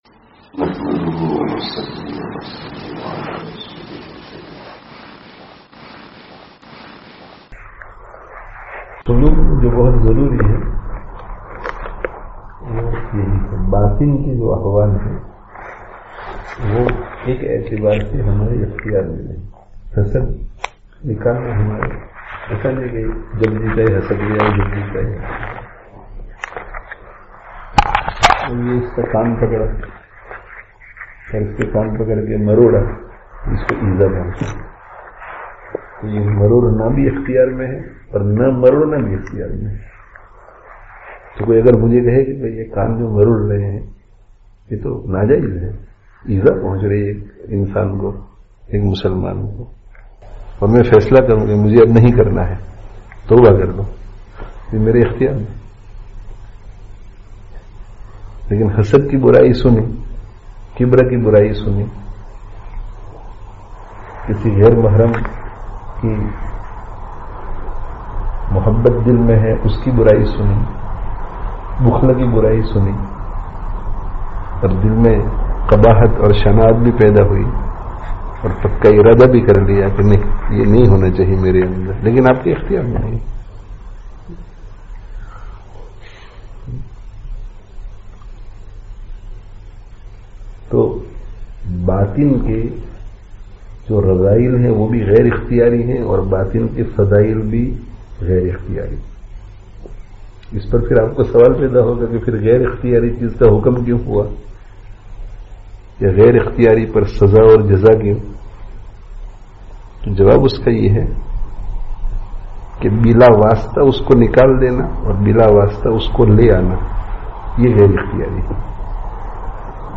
[Informal Majlis] Tazkiyah kī Āsān Tashrīh (25/08/20)